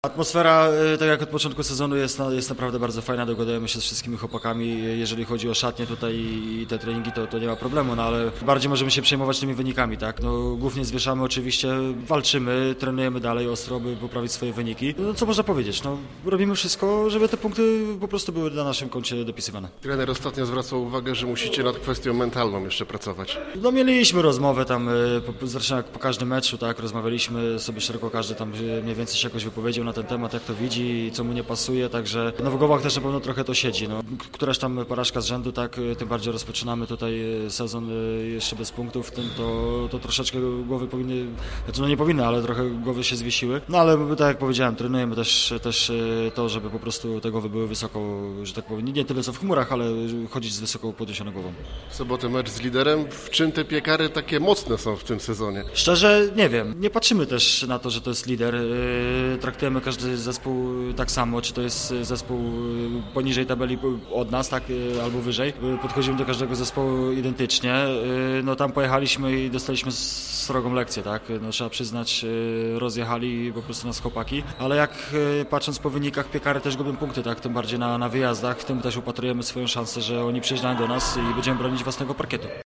Przed sobotnim meczem rozmawialiśmy z jednym z najbardziej doświadczonych zielonogórskich szczypiornistów.